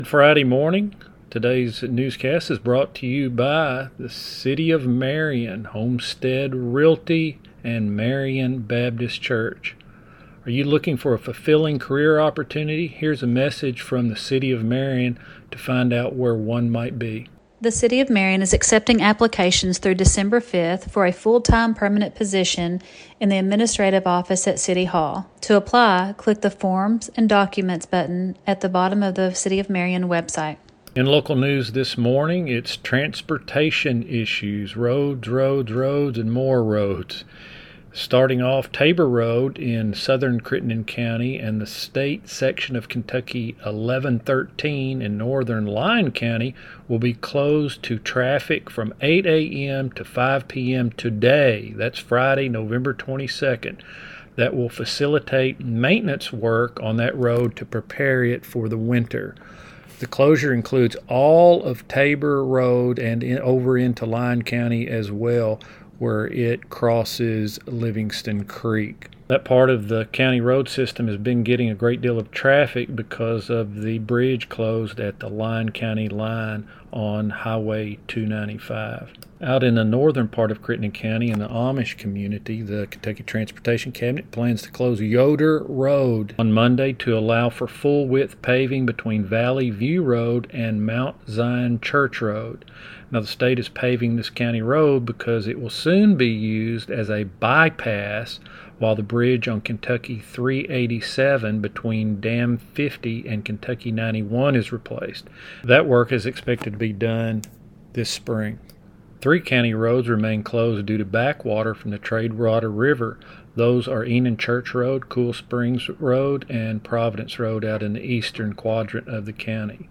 FRIDAY NEWScast | Traffic Updates+